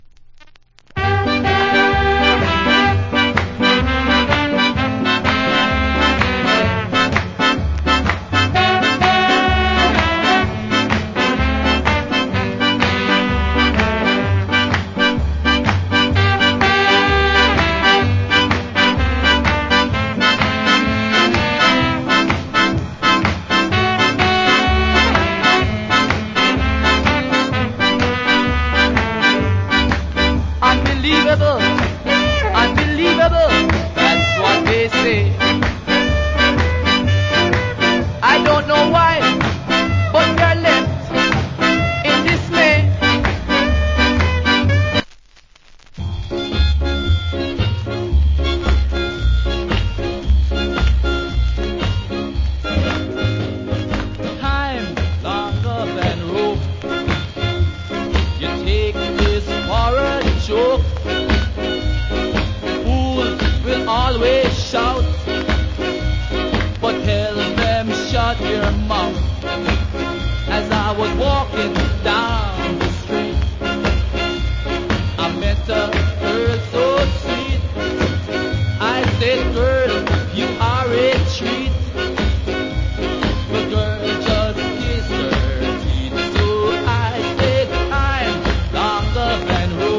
Nice Ska Vocal.